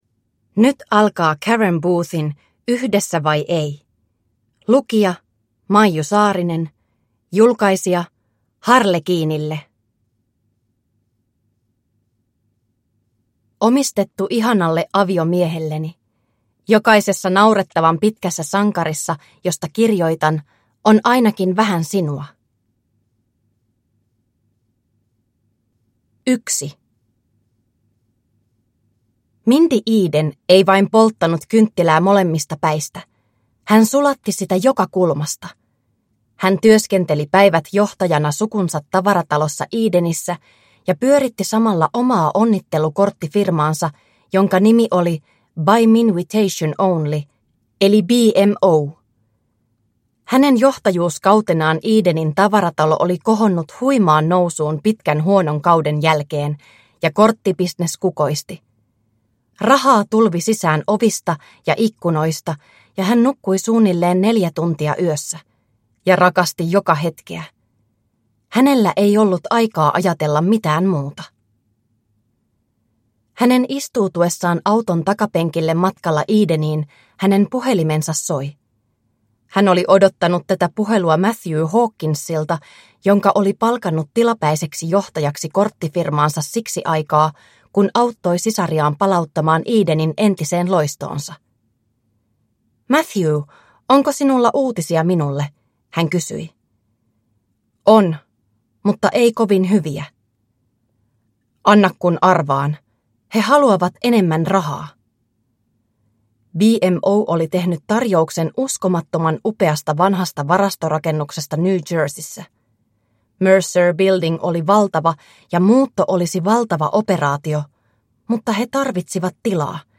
Yhdessä vai ei? (ljudbok) av Karen Booth